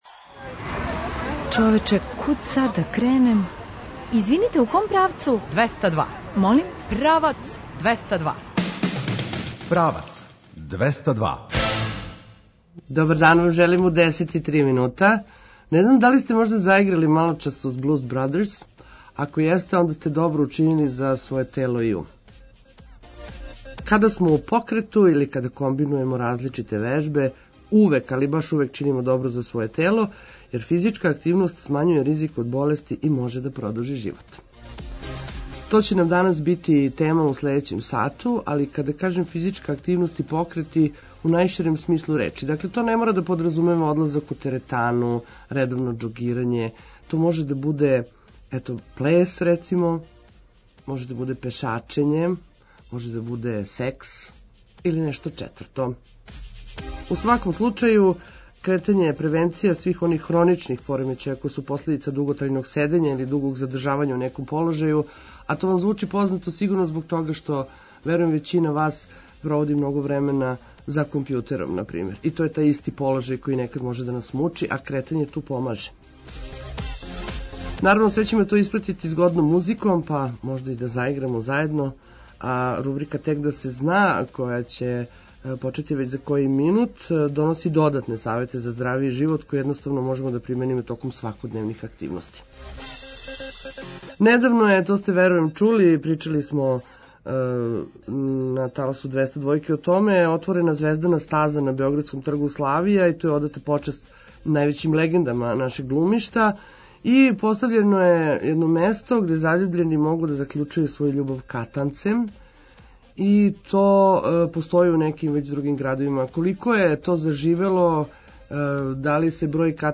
О томе говоримо у данашњој емисији, уз музику која покреће.
Наш репортер ће се јавити са недавно отворене „Звездане стазе” на београдском Тргу Славија, где је одата почаст највећим легендама домаћег глумишта.